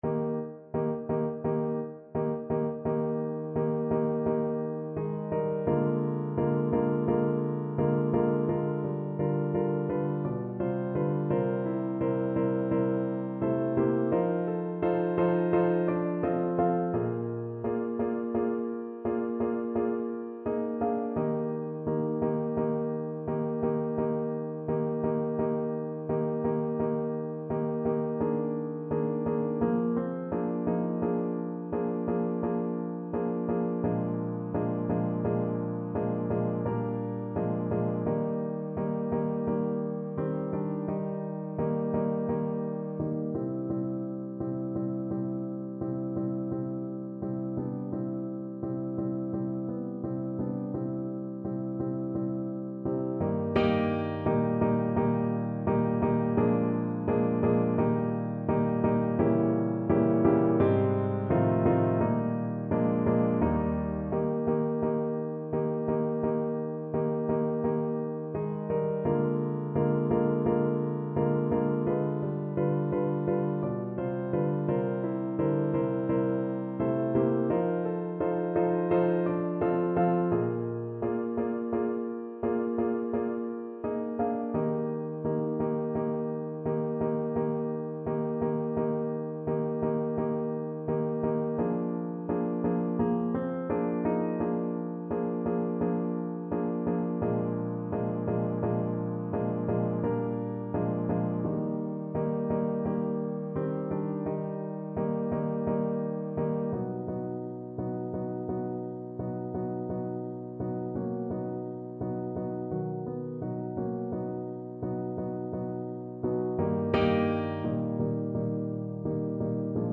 Play (or use space bar on your keyboard) Pause Music Playalong - Piano Accompaniment Playalong Band Accompaniment not yet available transpose reset tempo print settings full screen
Eb major (Sounding Pitch) (View more Eb major Music for Voice )
= 120 Etwas geschwind
2/4 (View more 2/4 Music)
D5-G6
Voice  (View more Intermediate Voice Music)
Classical (View more Classical Voice Music)